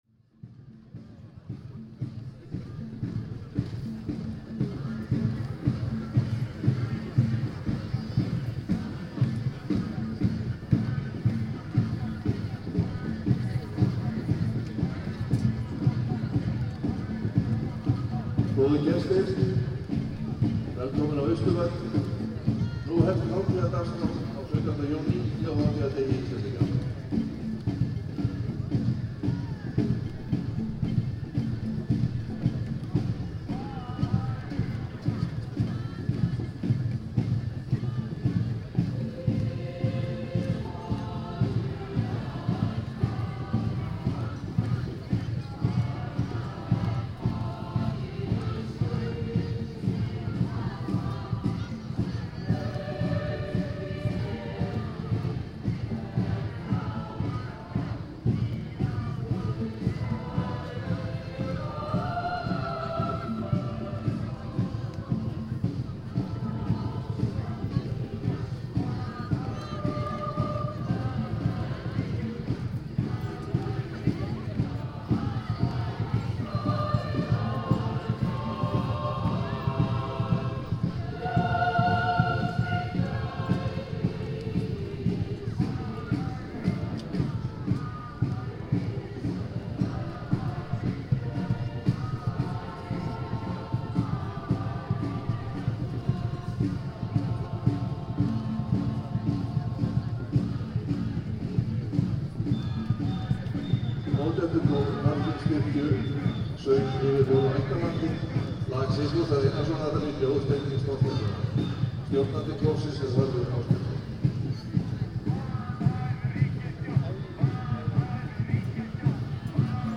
Protest on the Independence Day
The Independence Day, June 17, 2015, was a little different from what it usually is, on Austurvöllur. A few thousand people were supposed to attend a meeting there, to protest the government.